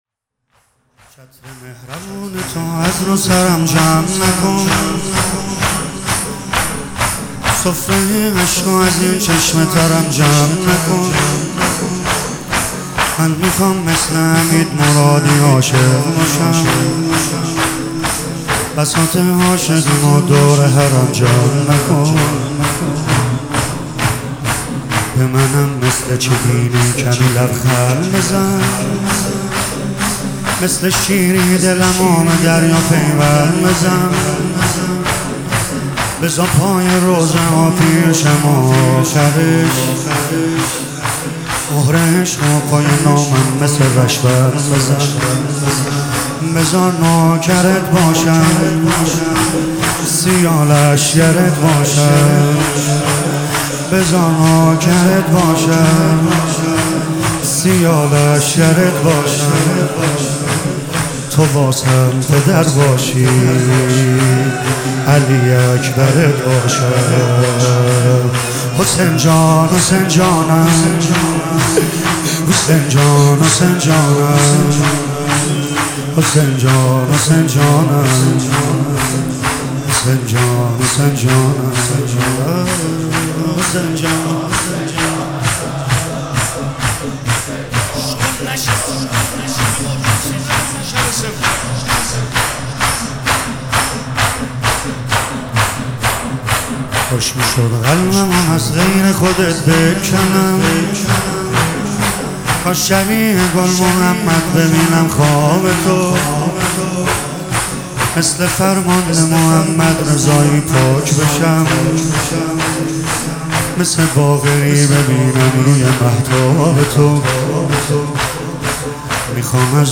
هیئت فدائیان حسین (ع) اصفهان